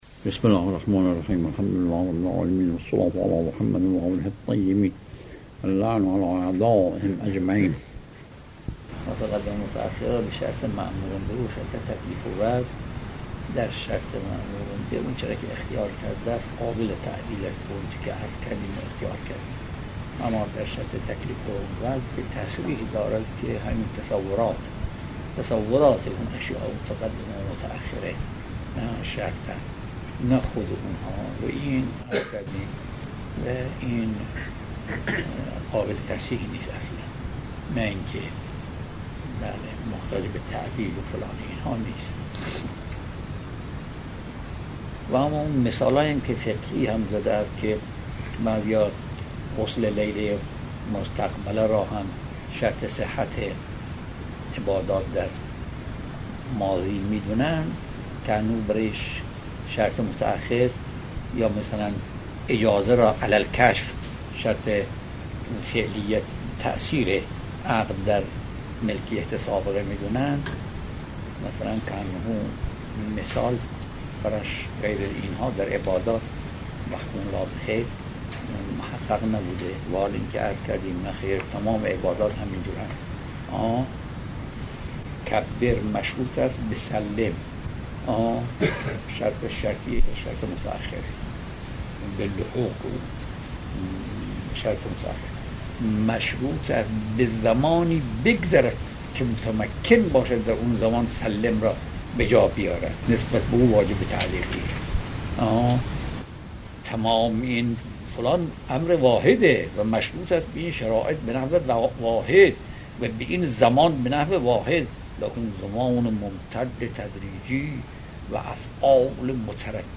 آيت الله بهجت - خارج اصول | مرجع دانلود دروس صوتی حوزه علمیه دفتر تبلیغات اسلامی قم- بیان